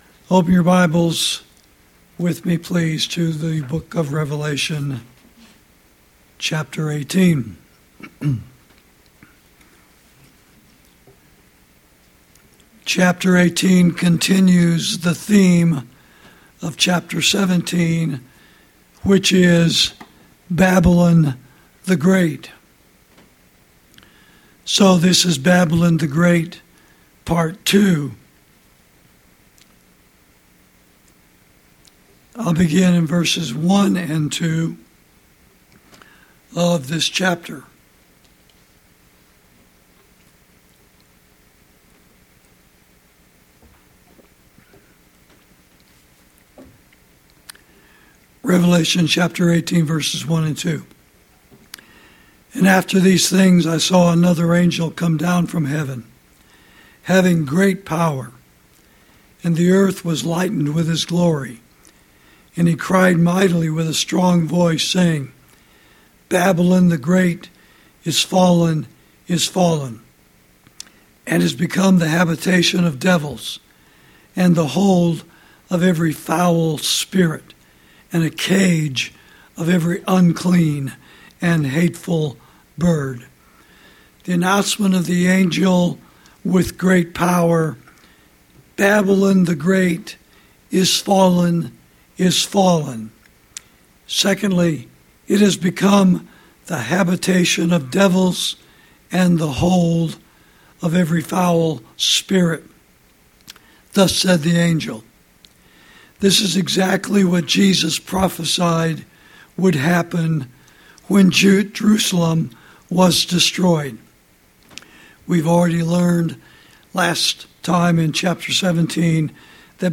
Sermons > Babylon The Great — Part Two — The Mighty City Thrown Down (Prophecy Message Number Twenty-Two)